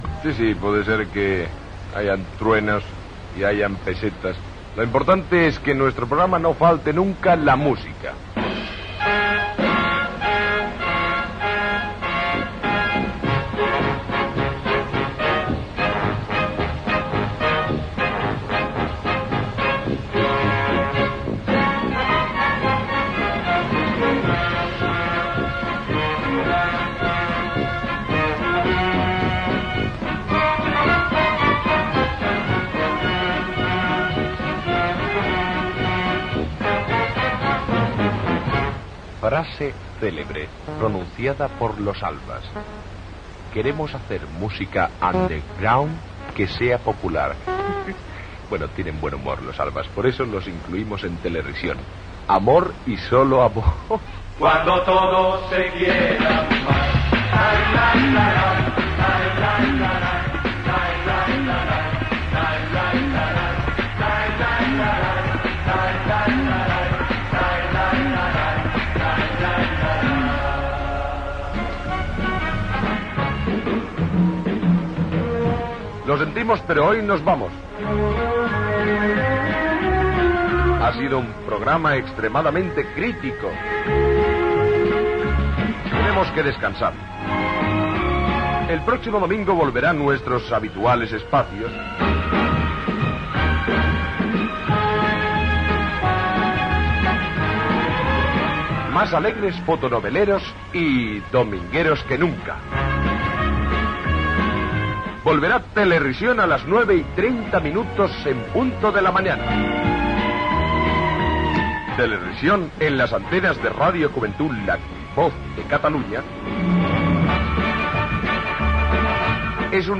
Música, frase cèlebre, música i comiat del programa amb identificació de l'emissora
Entreteniment